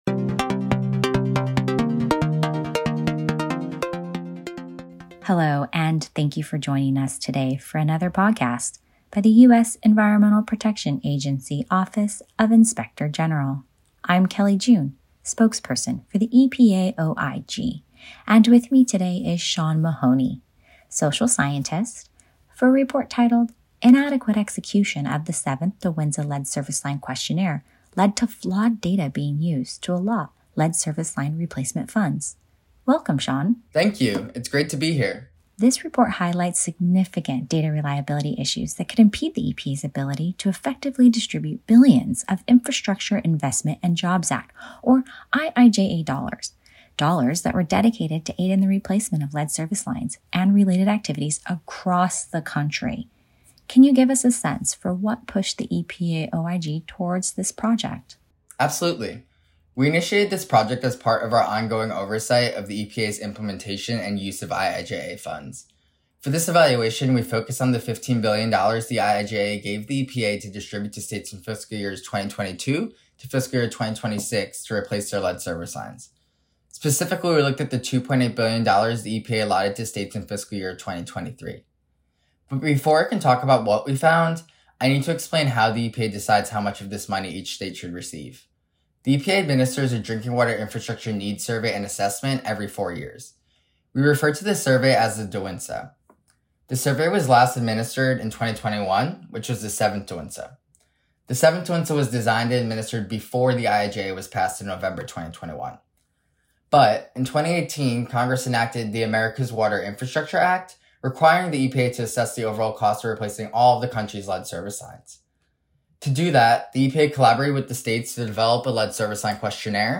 More Podcasts: Listen to our staff talk about their latest audit reports, investigative functions and other initiatives.